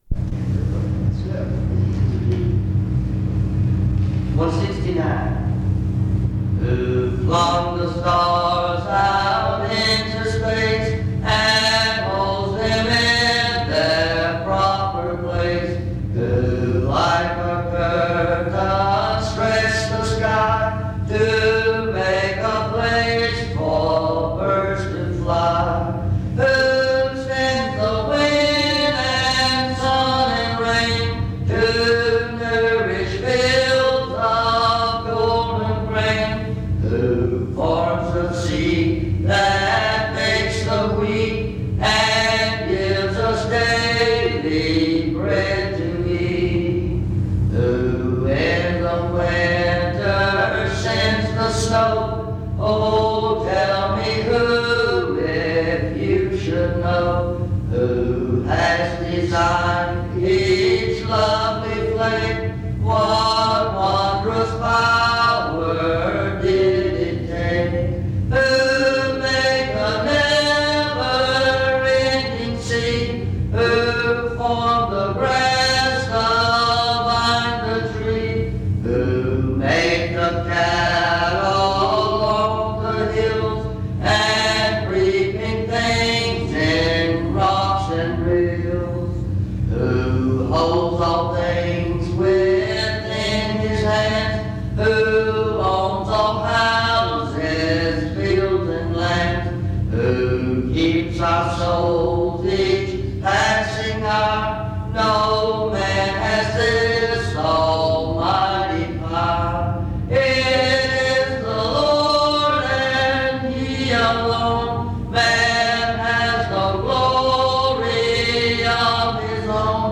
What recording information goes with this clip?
In Collection: Reidsville/Lindsey Street Primitive Baptist Church audio recordings Thumbnail Titolo Data caricata Visibilità Azioni PBHLA-ACC.001_076-A-01.wav 2026-02-12 Scaricare PBHLA-ACC.001_076-B-01.wav 2026-02-12 Scaricare